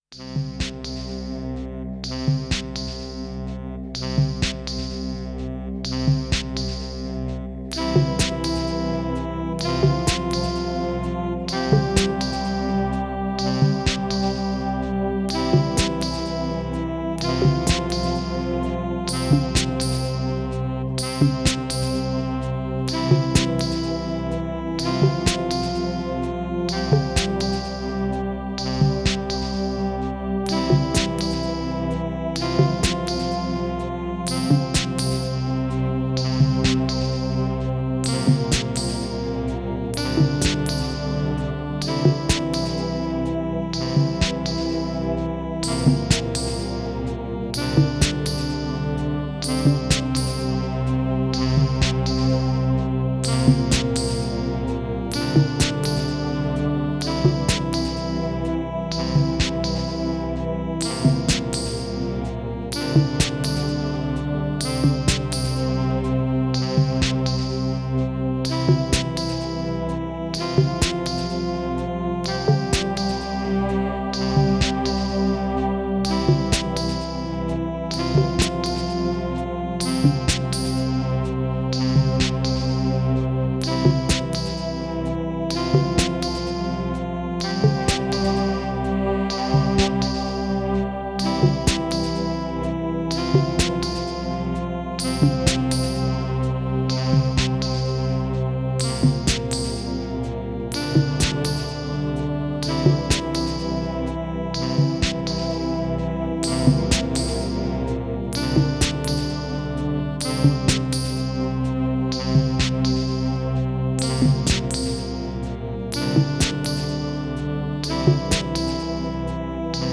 Tempo: 45 bpm / Datum: 09.04.2018